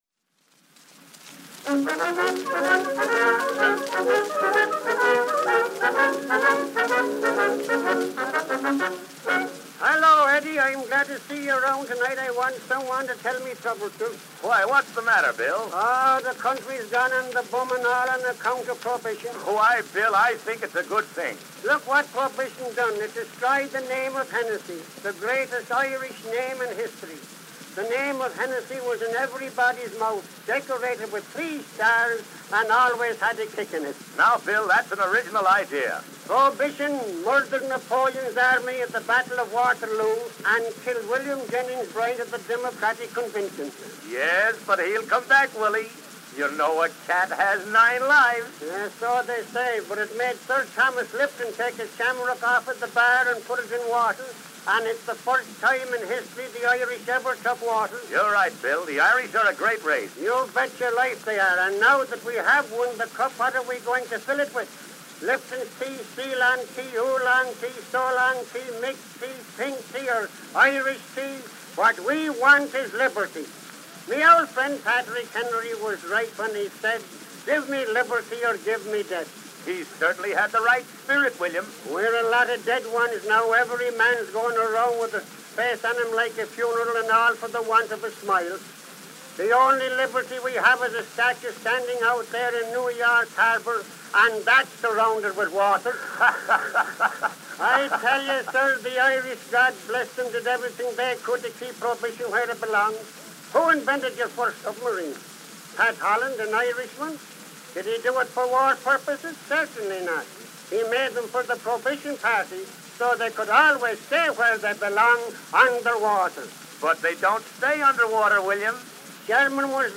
Dubbed from Edison Diamond Disc matrix 7465.
Irish monologue, orchestra accompaniment.
Vaudeville.